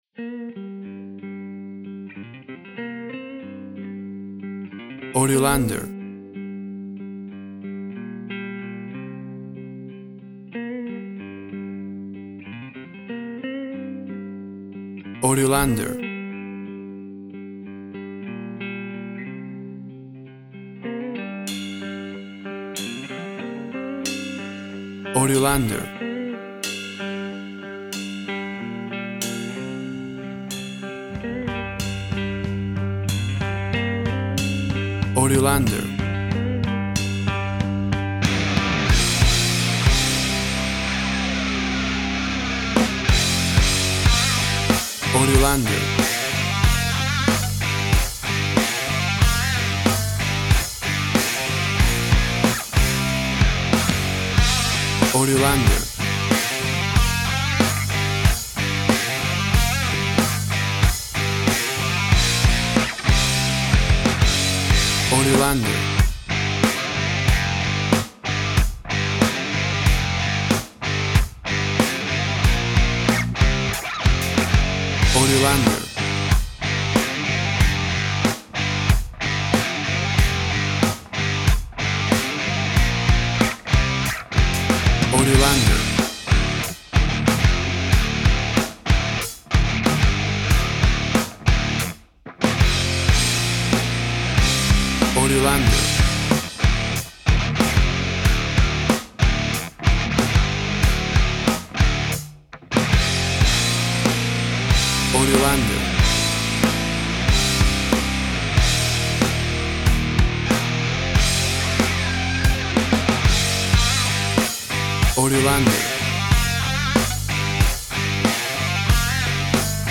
Tempo (BPM) 93